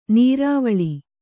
♪ nīrāvaḷi